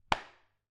冲压 " 冲压004
描述：打孔的声音。
Tag: SFX 冲头 命中 拍击